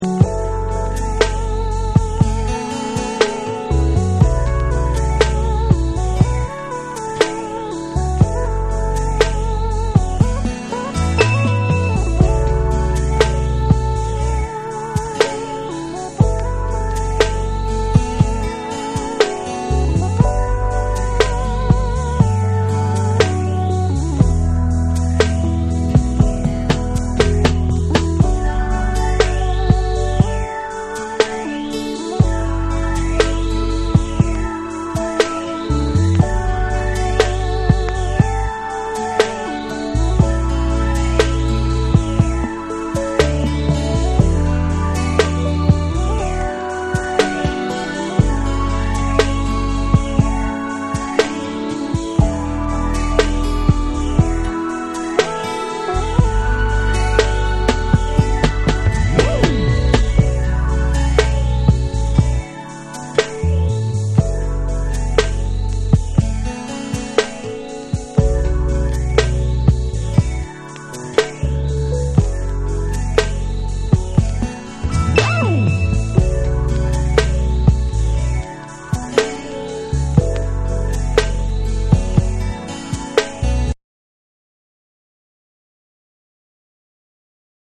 アーバン・メロウなトラックにハートウォーム／ソウルフルな歌声が染み渡る珠玉の1曲。
SOUL & FUNK & JAZZ & etc / ALL 500YEN